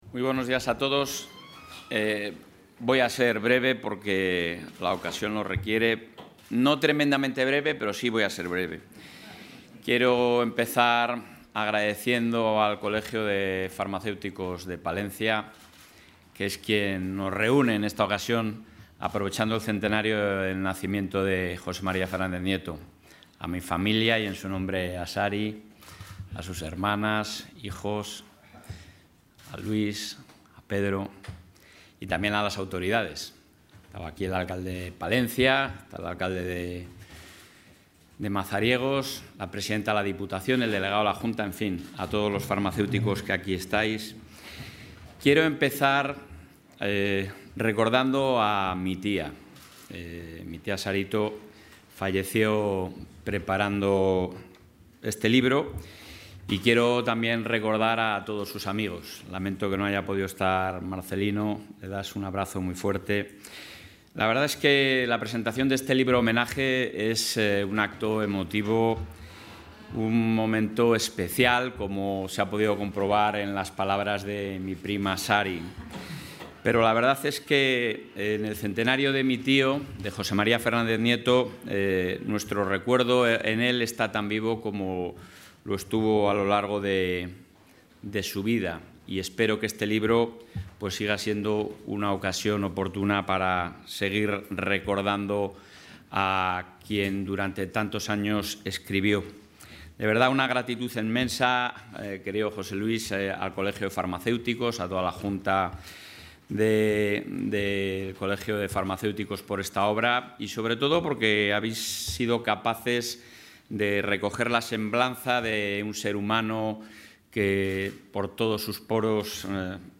El presidente de la Junta de Castilla y León, Alfonso Fernández Mañueco, ha participado hoy, en Palencia, en la...
Intervención del presidente.